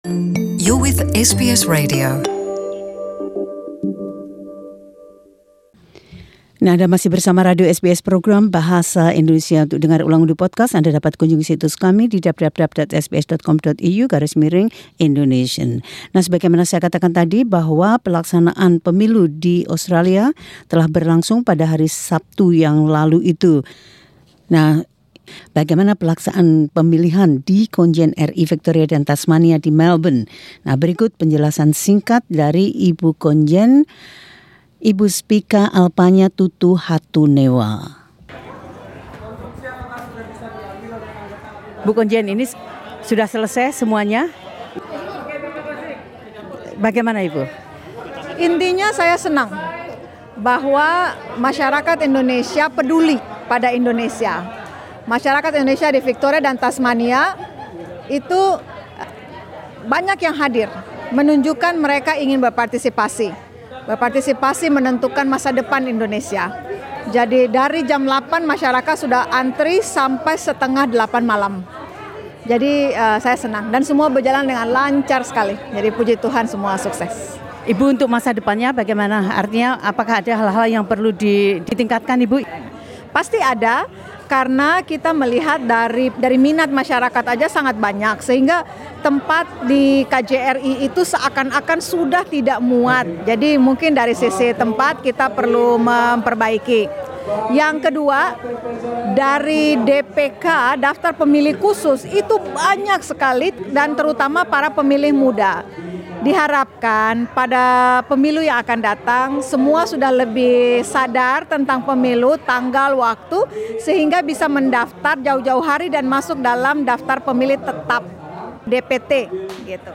Konsul Jenderal Spica Alphanya Tutuhatunewa memberikan kesannya.